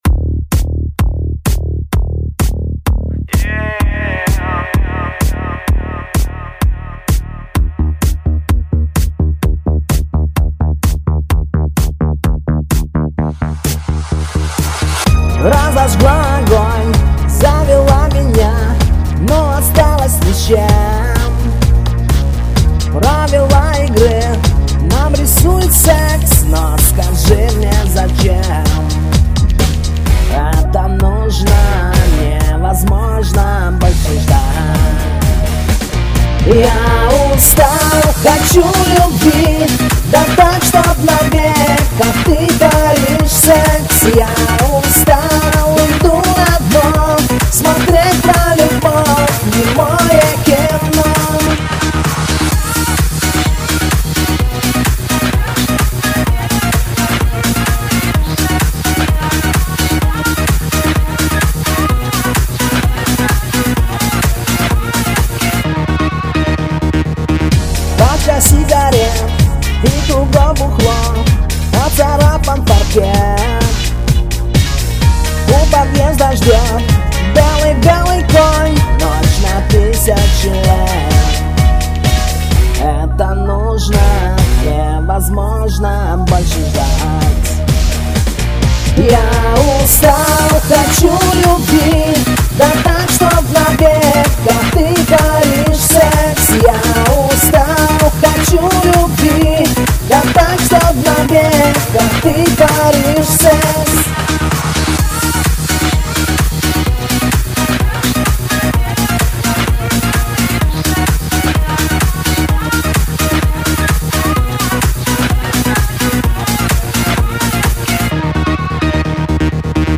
но  звучание  сбалансированное!